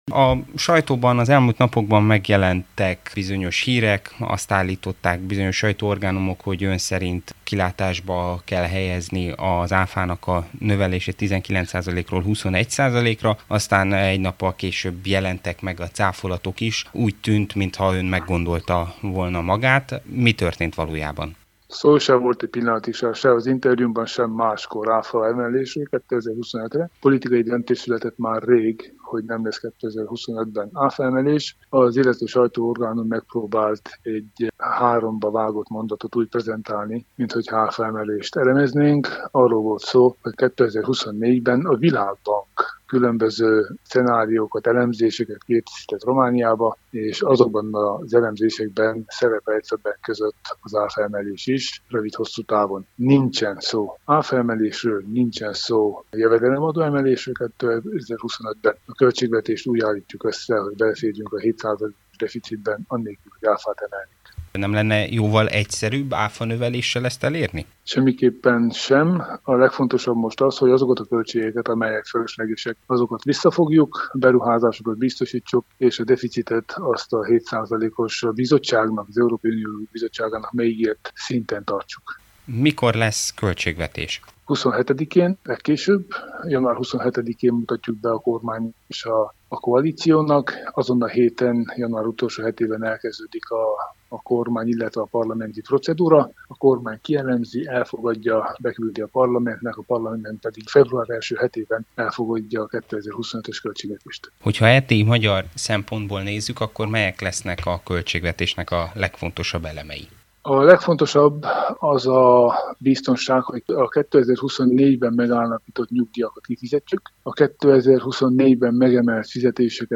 Tánczos Barna kormányfőhelyettest, pénzügyminisztert kérdezte